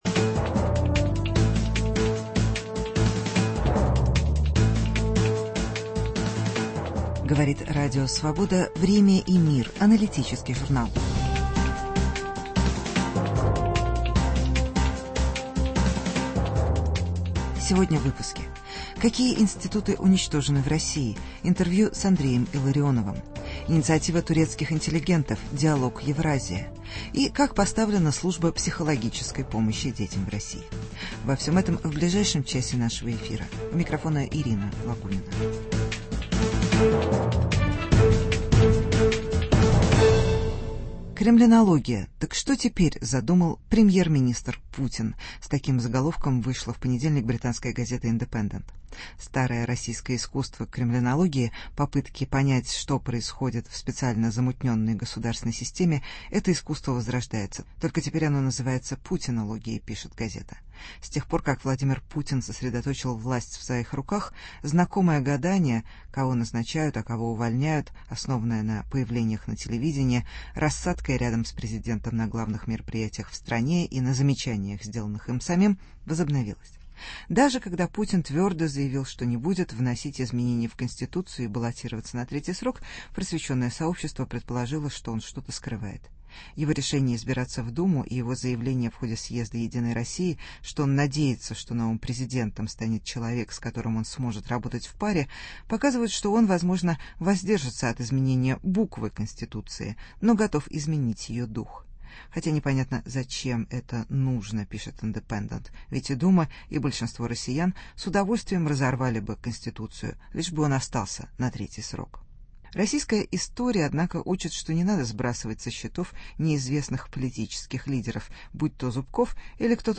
Какие институты отсутствуют в России: интервью с Андреем Илларионовым.